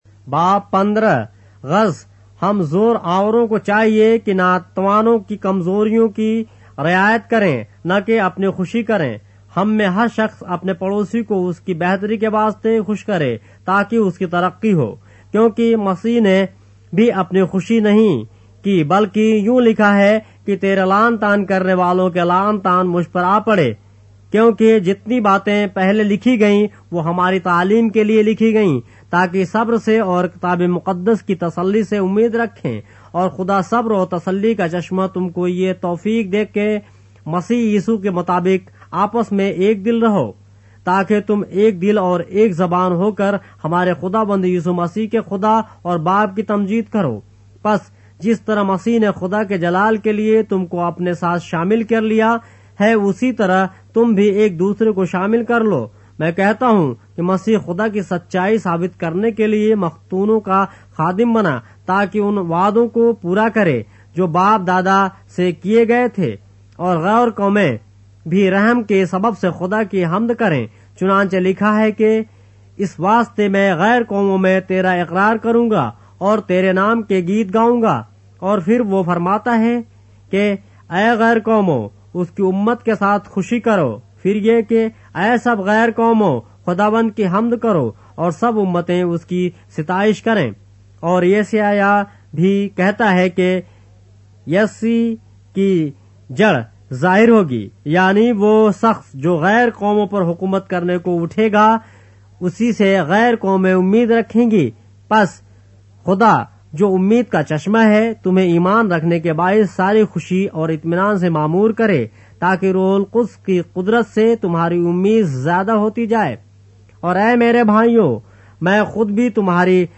اردو بائبل کے باب - آڈیو روایت کے ساتھ - Romans, chapter 15 of the Holy Bible in Urdu